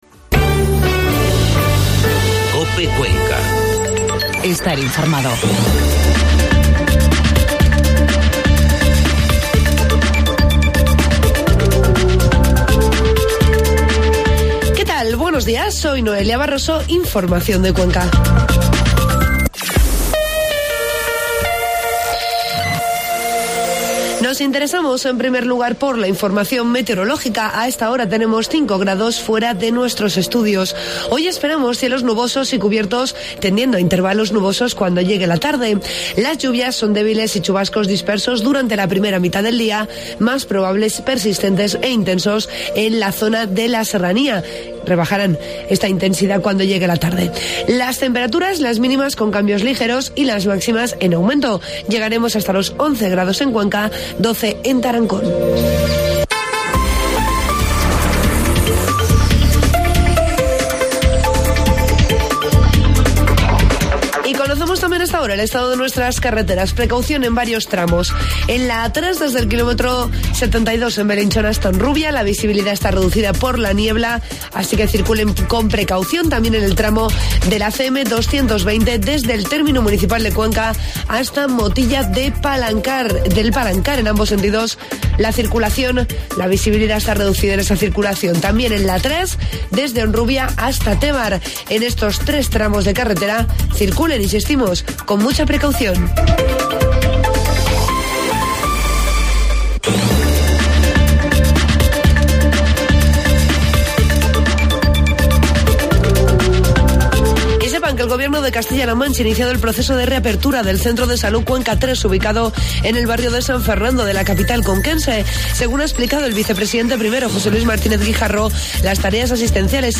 AUDIO: Informativo matinal